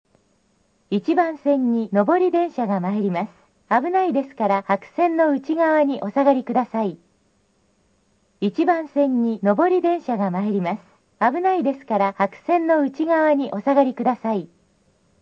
2008年7月、メロディー+放送が導入された。車掌がスイッチを押すと自動で流れるシステムで、両線ともに曲の最後まで流れない。
接近放送（女性）
発車メロディー   不明 MD